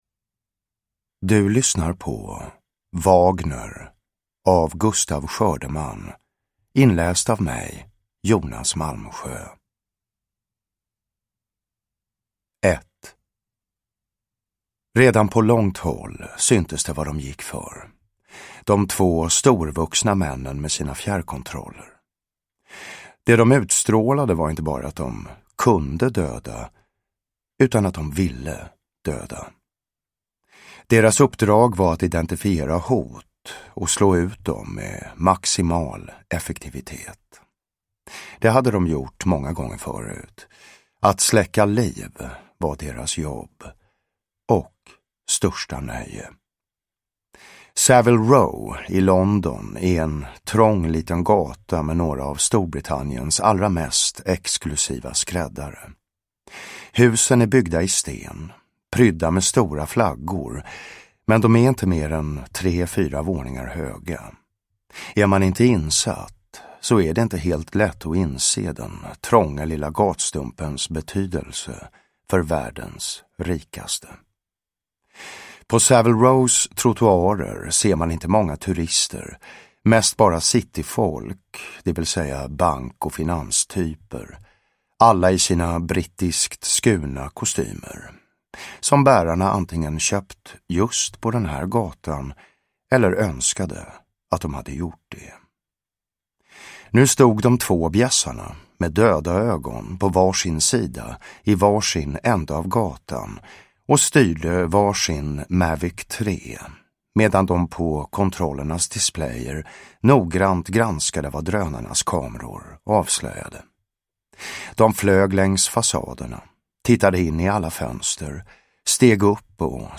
Wagner – Ljudbok – Laddas ner
Uppläsare: Jonas Malmsjö